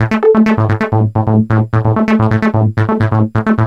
SONS ET LOOPS GRATUITS DE BASSES DANCE MUSIC 130bpm
Basse dance 2 G